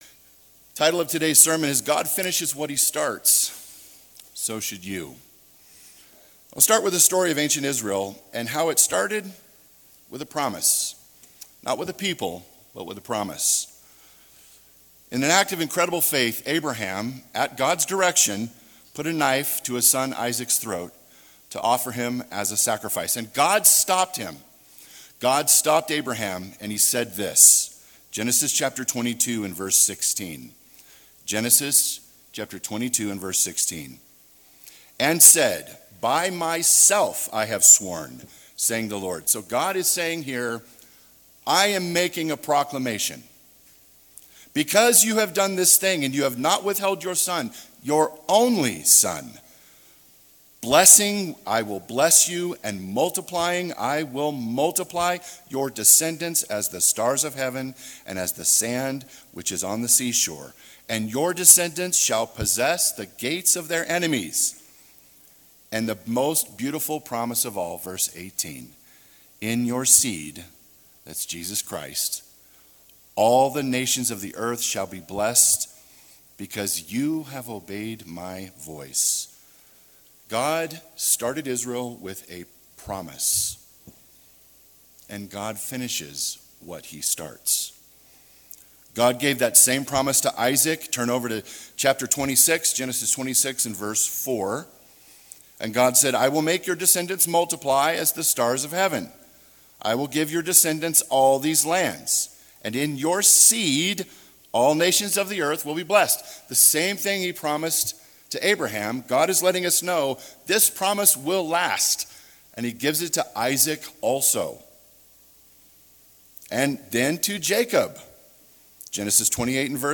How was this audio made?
This sermon was given at the Estes Park, Colorado 2023 Feast site.